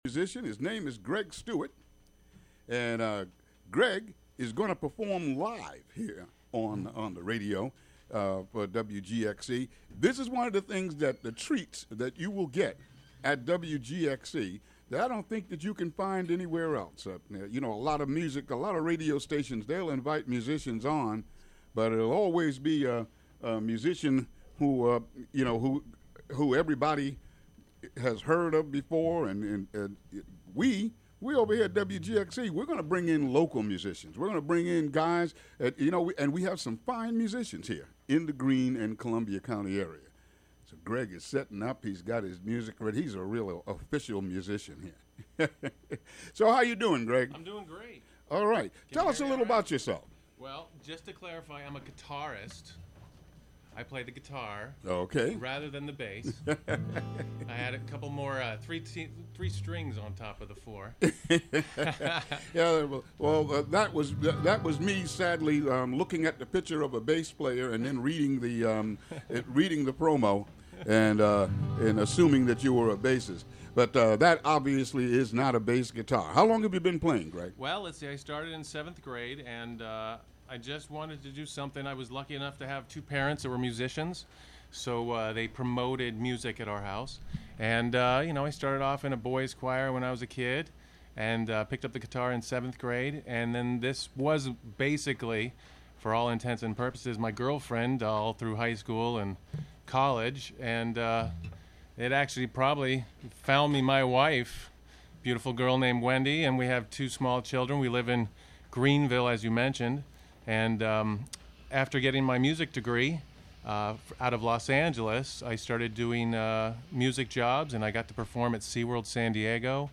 Interview and performance